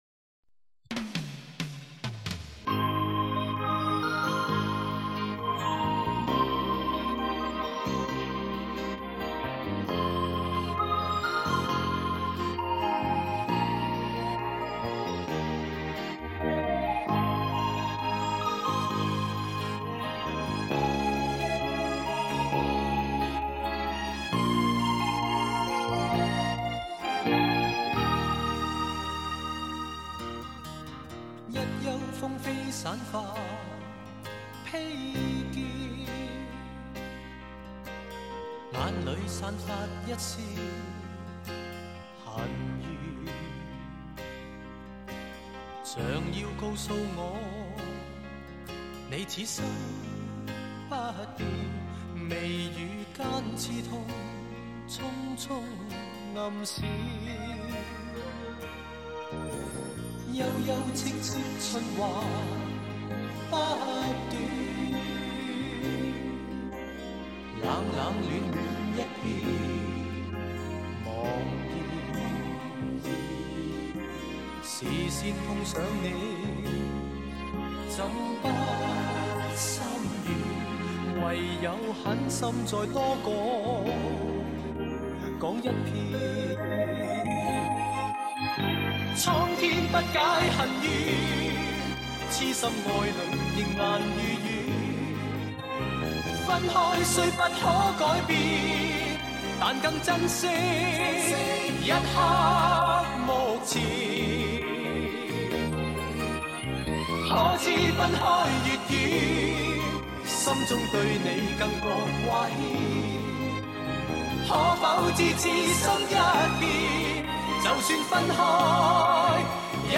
港台